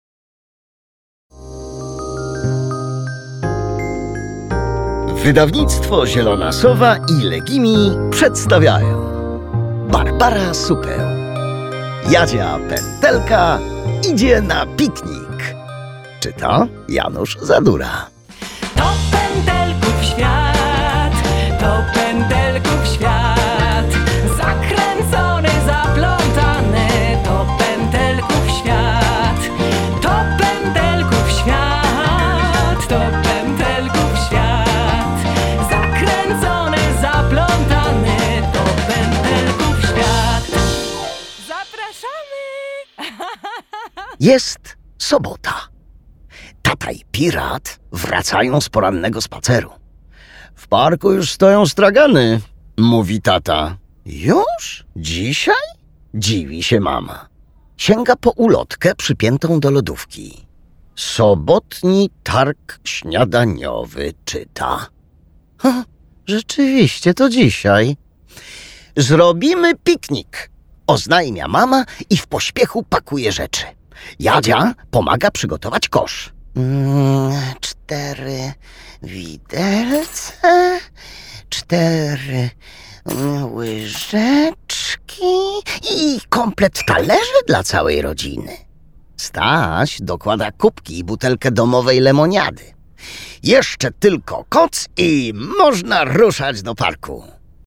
Jadzia Pętelka idzie na piknik - Barbara Supeł - audiobook + książka